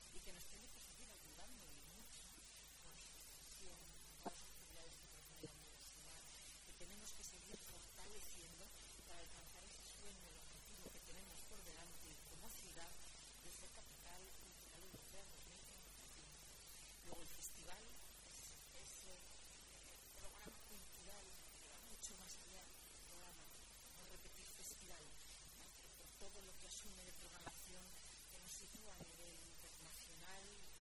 La alcaldesa nos habla del festival de música y danza